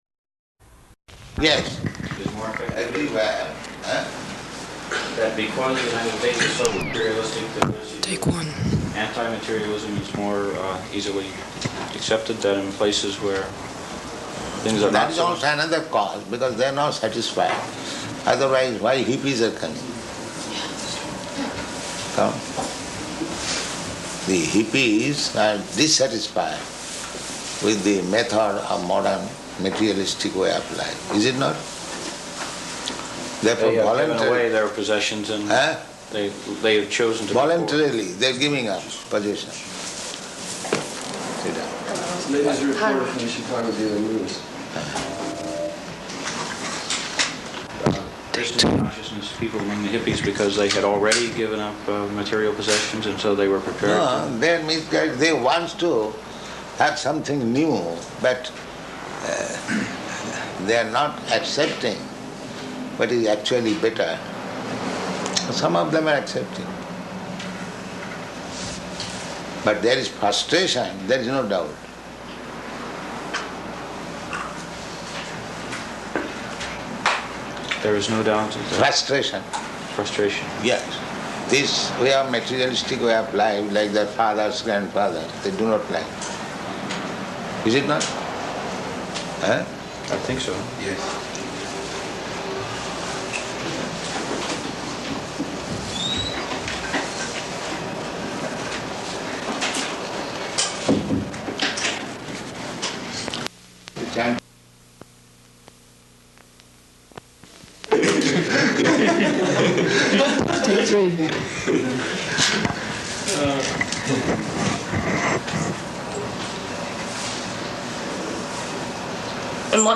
Type: Interview
Location: Chicago